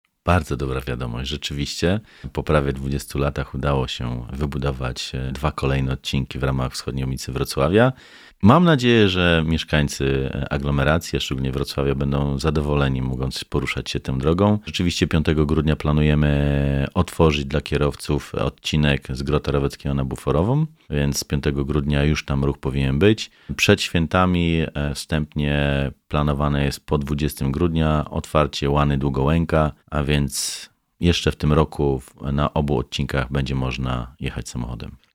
Mówi Tymoteusz Myrda – Członek Zarządu Województwa Dolnośląskiego.